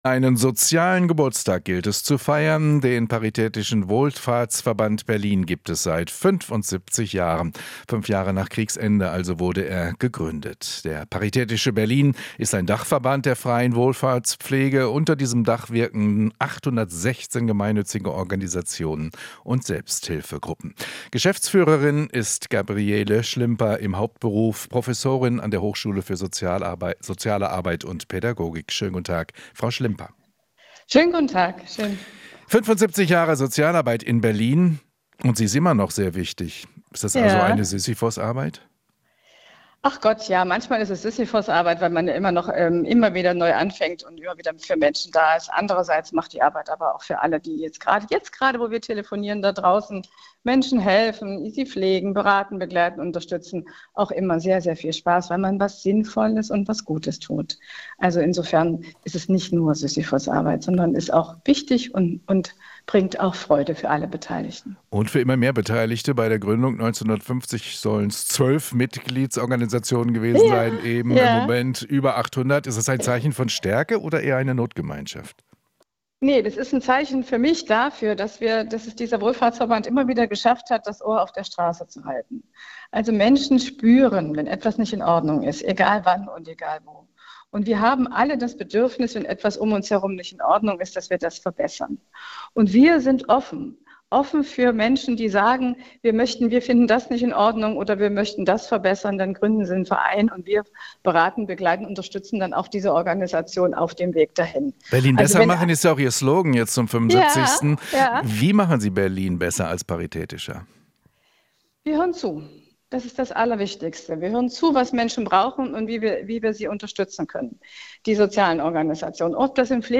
Interview - 75 Jahre Paritätischer Wohlfahrtsverband Berlin: "Wir hören zu"